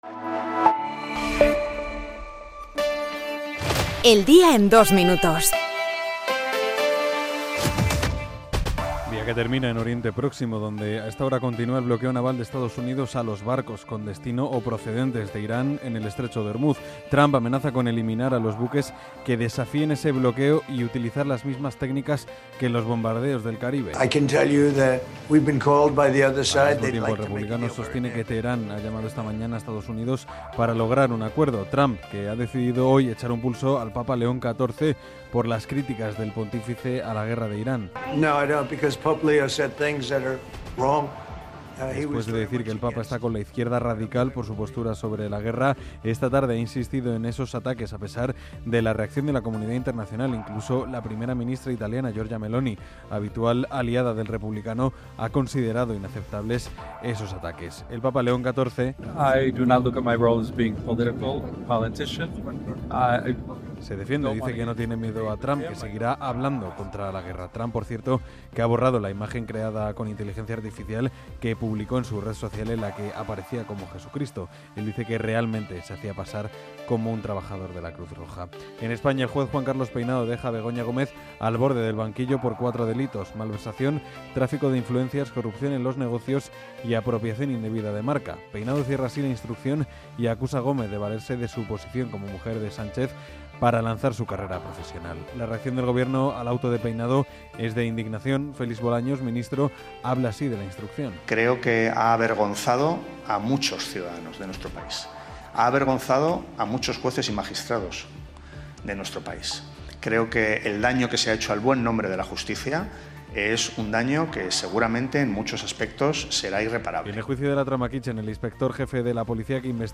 El resumen de las noticias de hoy